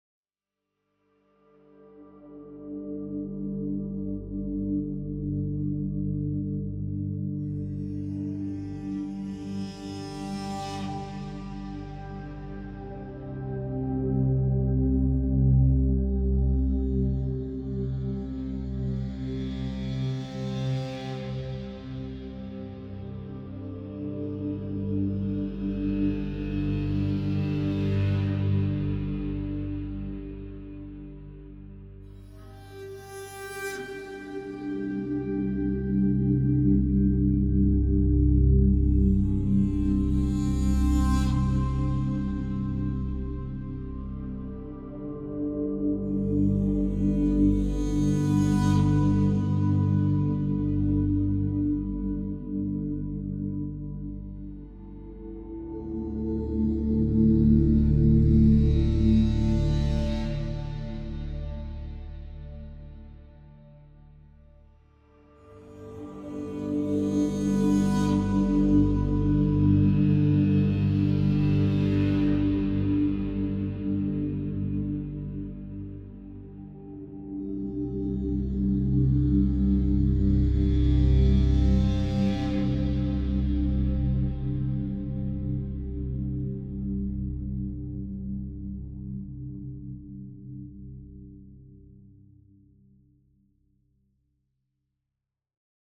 Added Ambient music pack. 2024-04-14 17:36:33 -04:00 26 MiB Raw History
Ambient Wonderful Intensity 1.wav